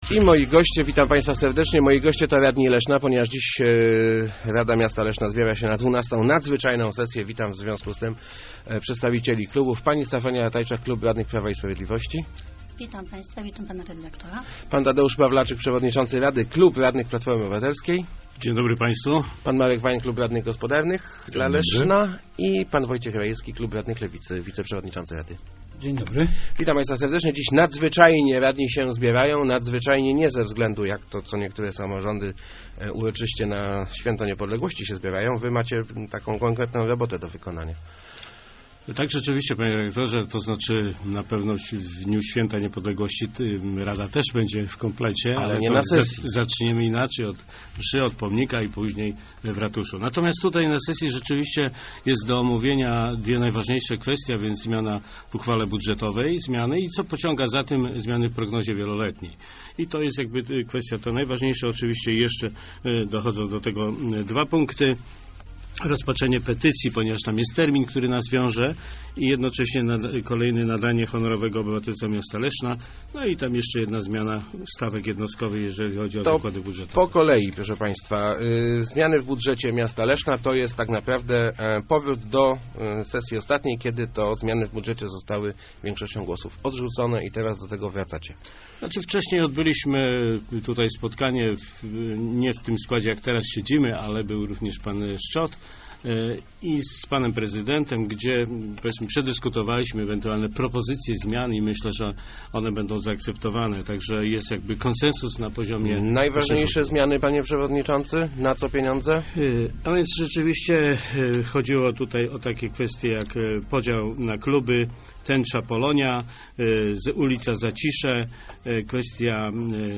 Jest zgoda na zmiany w budżecie - mówili w Rozmowach Elki przedstawiciele klubów Rady Miasta Leszna. Radni zdecydują między innymi o zwiększeniu wsparcia dla klubów sportowych; 100 tysiecy złotych wyniesie też nagroda za srebny medal dla żużlowców Unii Leszno.